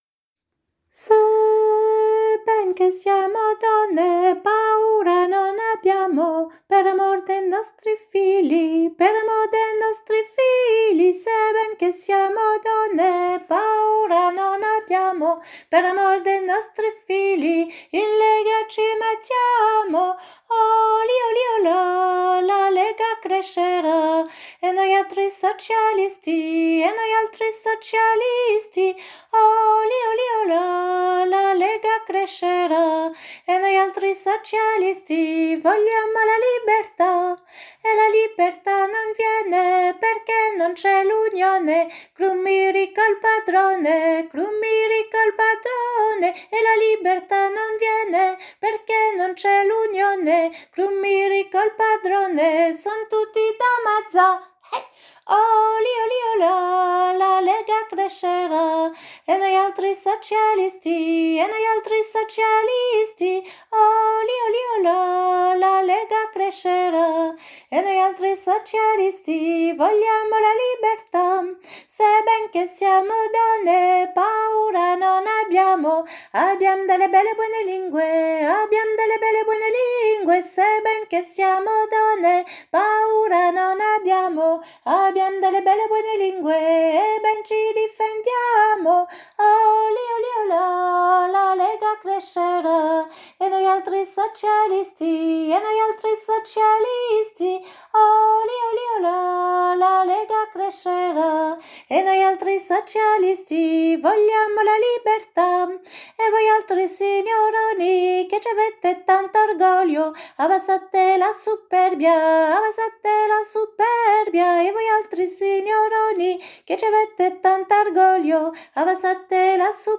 La Lega est un chant populaire des « Mondine », repiqueuses de riz de la vallée du Po.
–> Voix par voix (par la Chorale des Sans Nom) : tutti
cbcc6-la-lega-soprane.mp3